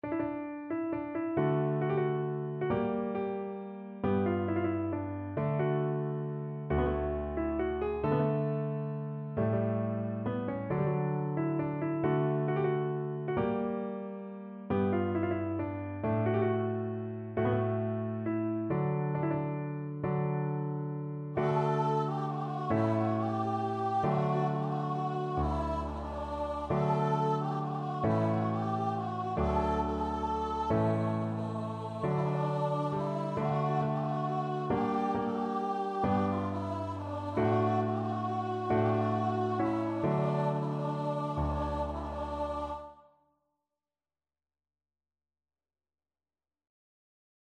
Free Sheet music for Voice
D major (Sounding Pitch) (View more D major Music for Voice )
One in a bar .=45
3/4 (View more 3/4 Music)
A4-A5
World (View more World Voice Music)